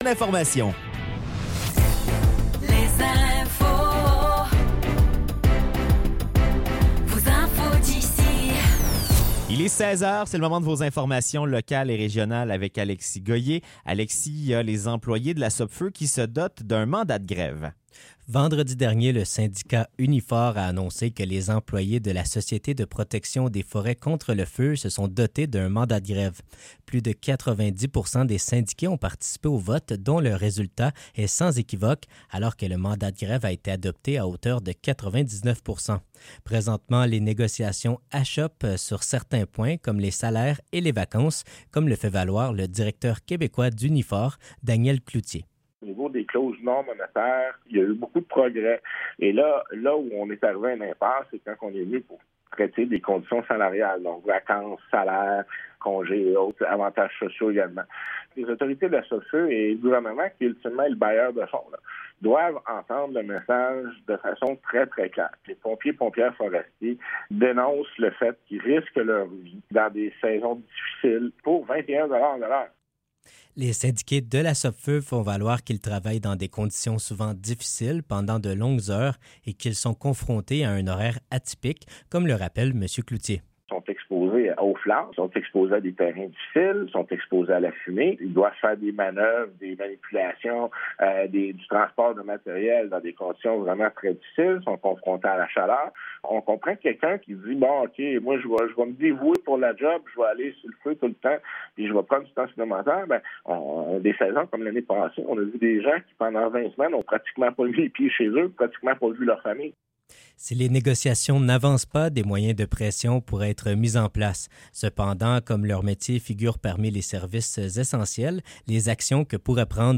Nouvelles locales - 15 mai 2024 - 16 h